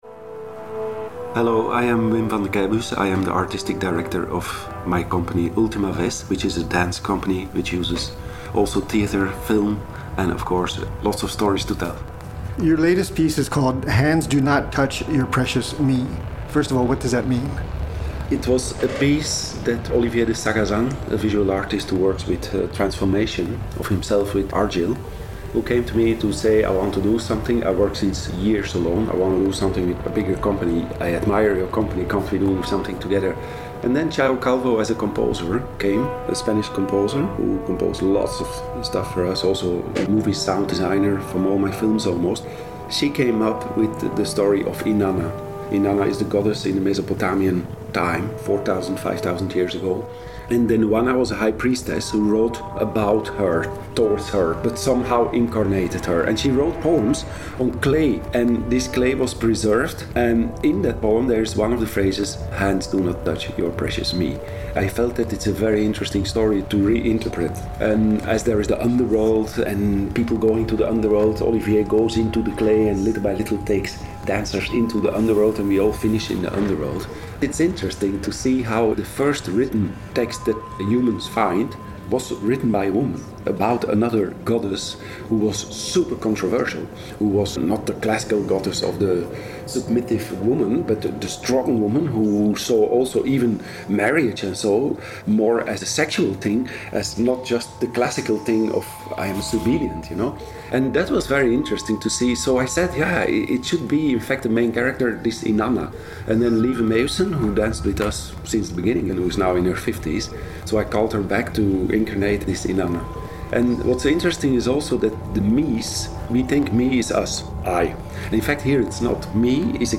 Based on five-millennia-old Mesopotamian poetry, Hands Do Not Touch Your Precious Me is a mysterious, poetic, gruesome, and fascinating descent into the underworld. Vandekeybus took a break from rehearsals to answer our questions.